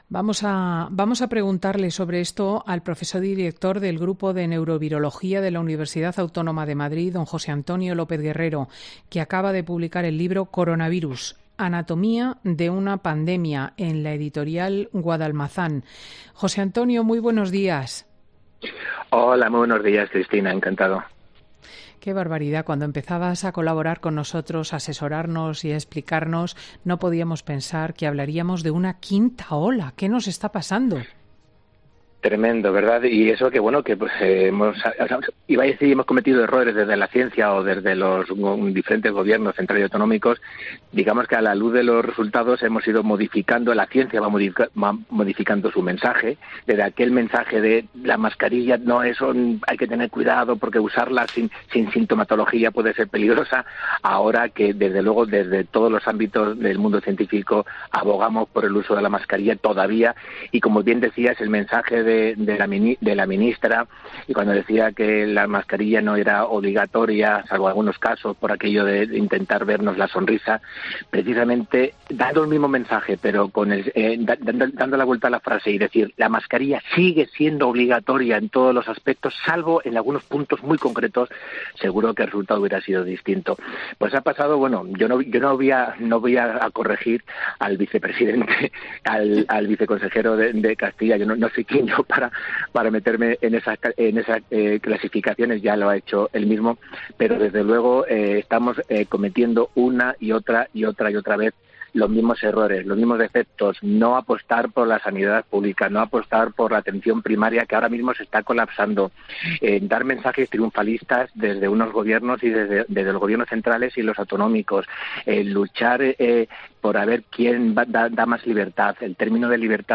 ponía en duda este domingo en los micrófonos de COPE la necesidad de invertir en estos momentos en una tercera dosis de la vacuna del coronavirus.